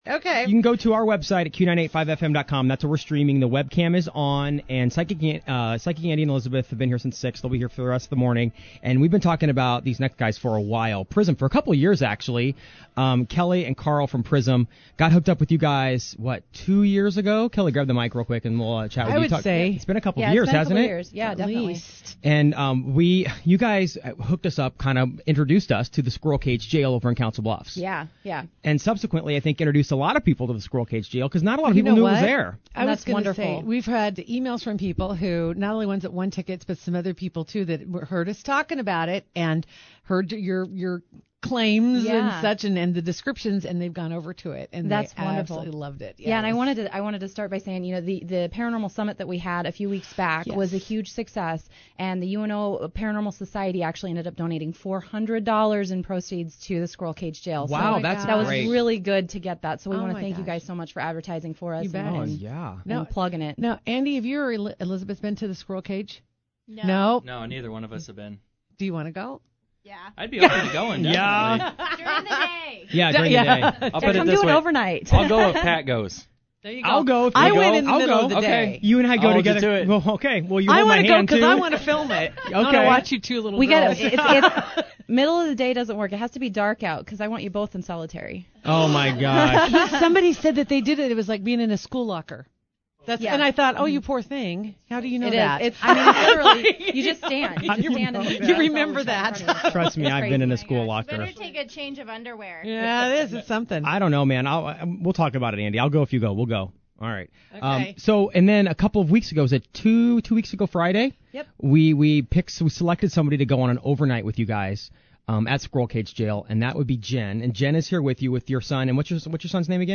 Several new EVPs from the historic Squirrel Cage Jail were played.